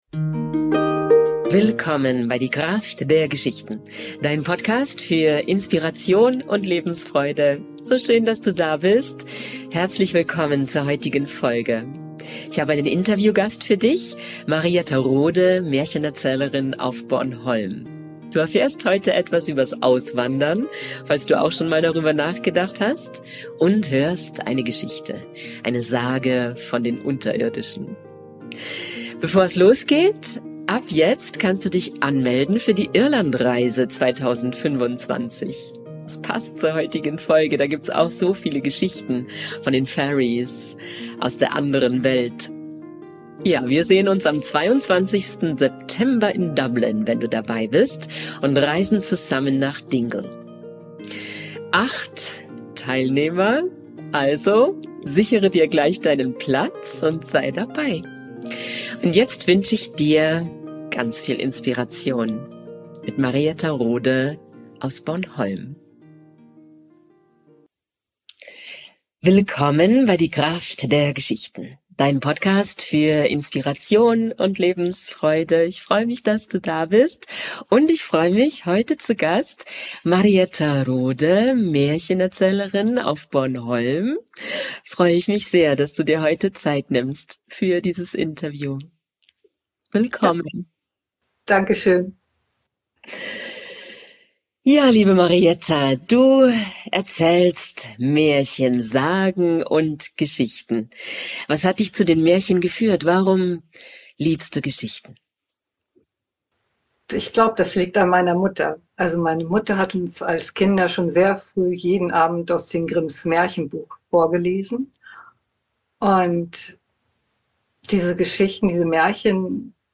Nicht wundern, die Internetverbindung bei diesem Interview war nicht ganz stabil, die Unterirdischen erzählen auch ein bisschen mit;-)